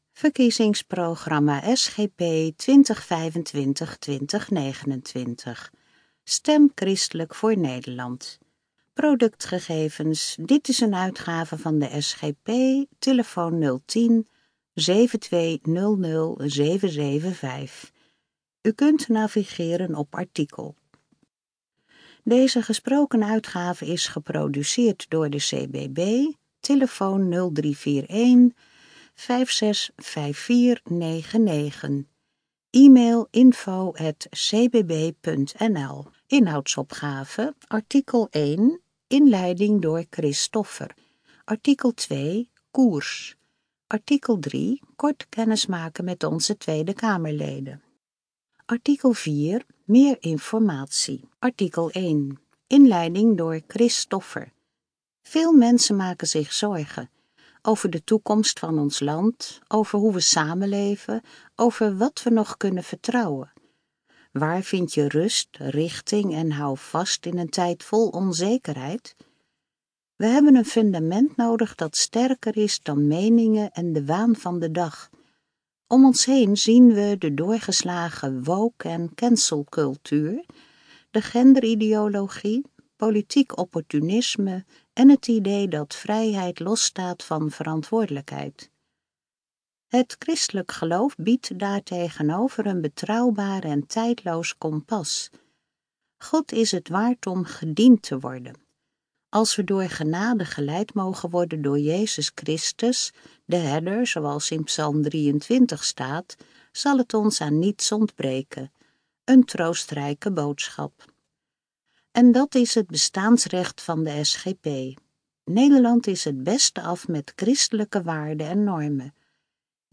TK 2025 | Verkiezingsprogramma in audio (ingesproken)